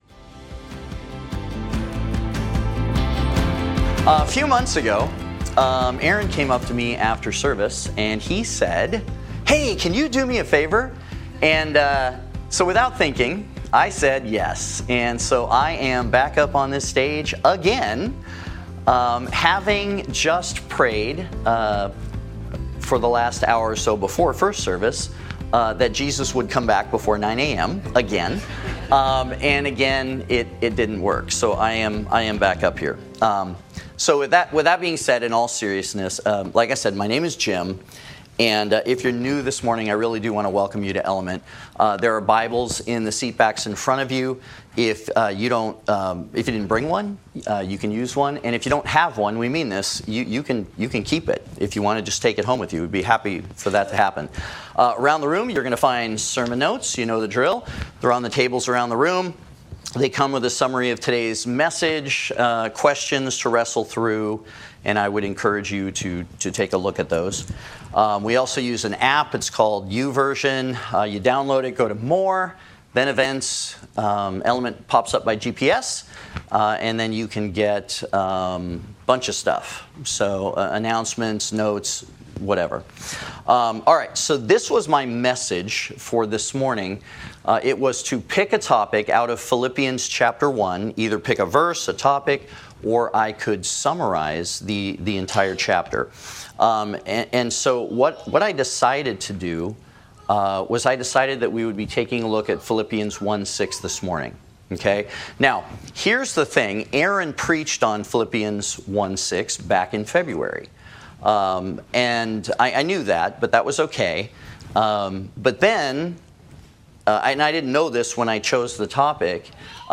Service Audio Message David Martyn Lloyd-Jones once said that Philippians 1:6 may very well be described as the fundamental (or key) verse to all of Paul’s letter to the church in Phillipi.